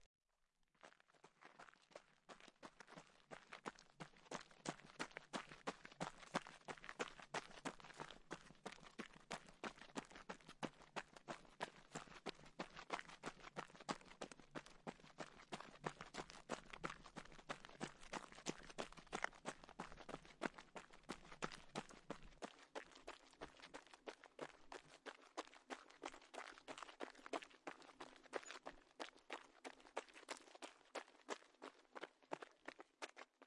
晨间慢跑
描述：早晨慢跑时使用悬臂麦克风录制的早晨慢跑
声道立体声